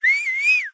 Мужчина радостно свистит